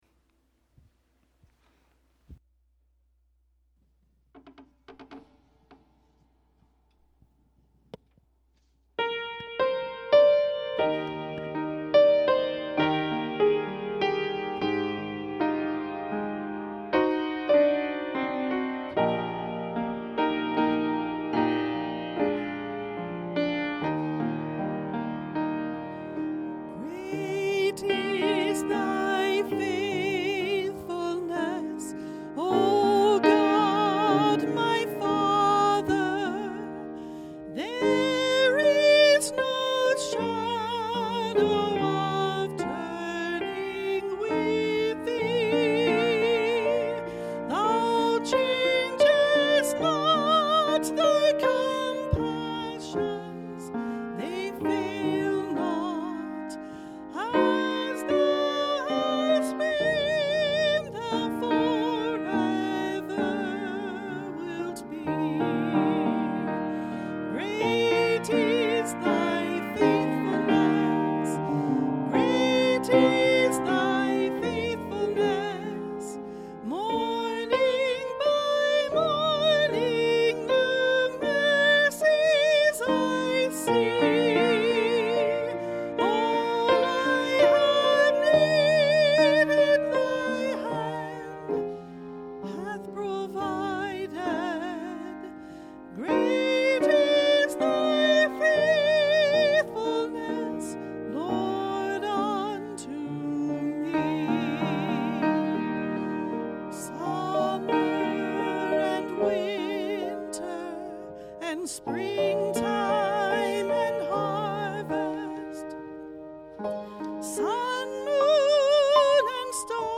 Sunday Sermon January 3, 2021